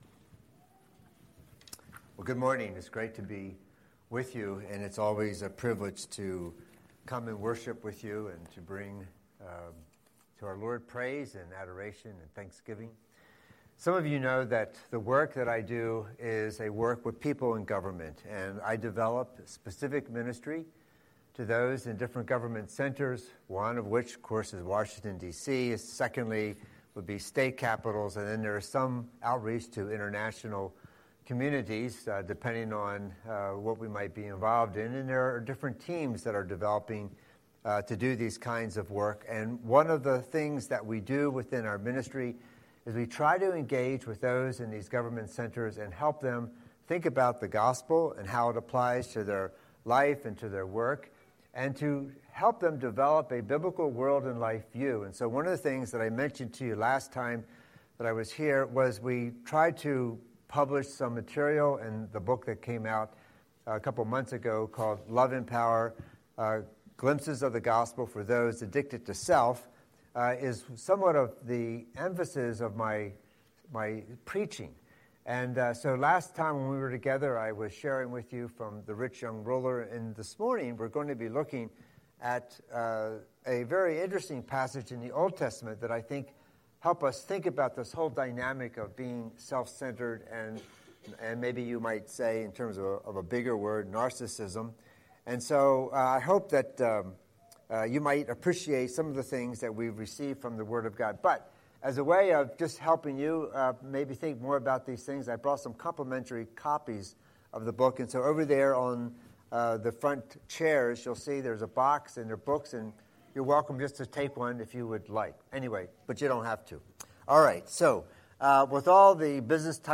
Scripture: 2 Kings. 15:1–7; 2 Chronicles 26:1–23 Series: Sunday Sermon